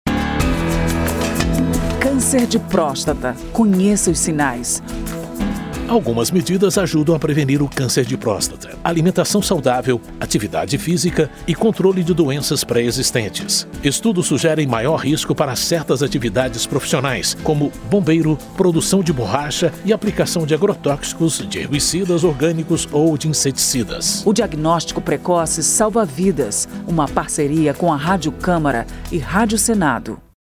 spot-cancer-de-prostata-04-parceiras.mp3